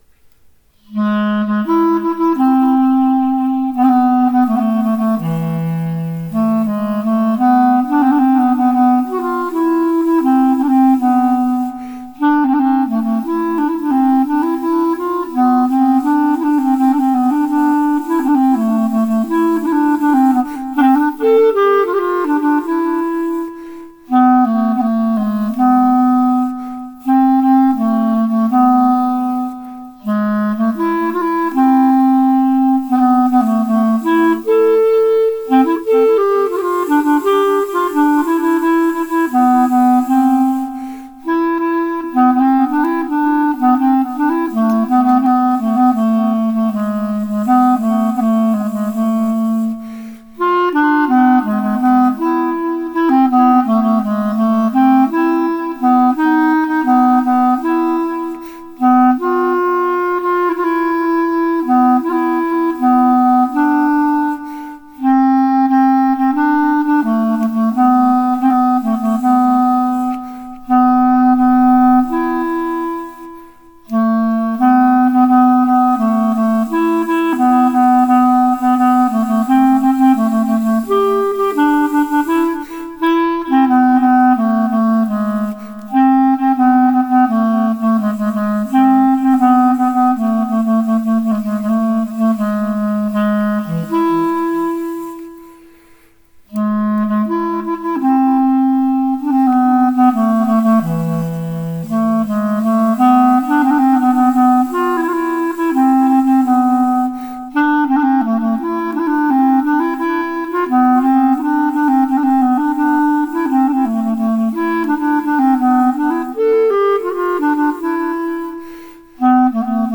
night tune - Chamber Music - Young Composers Music Forum
It is played by clarinet with some added echo.